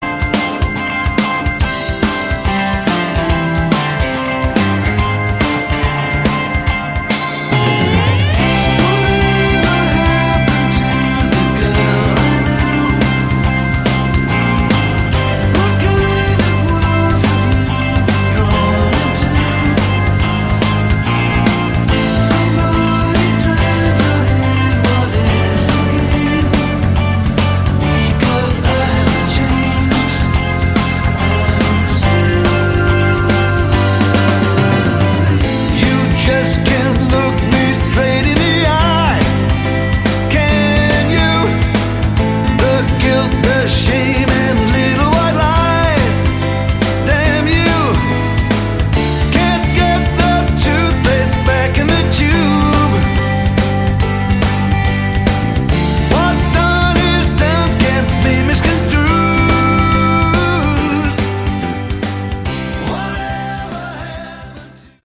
Category: AOR